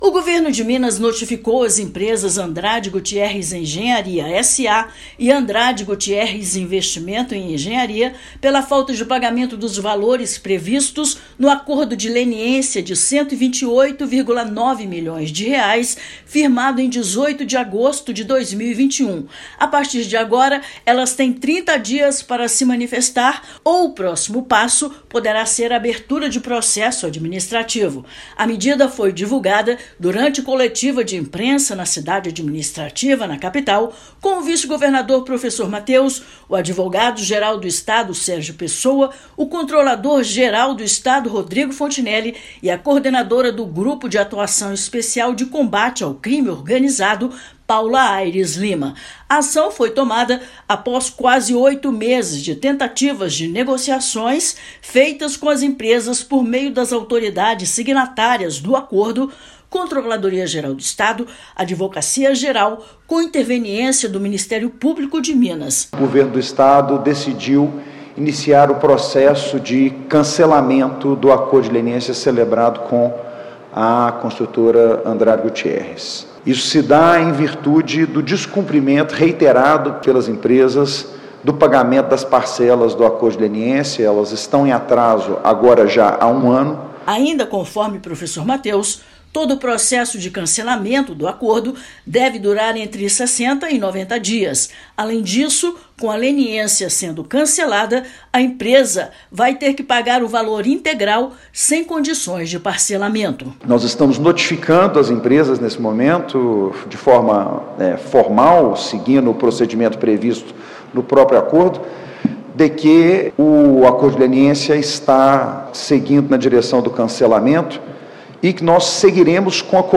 Estado dá prazo de 30 dias para que empresas envolvidas em fraudes em licitações de obras regularizem a situação, sob pena de abertura de processo administrativo e outras sanções. Ouça matéria de rádio.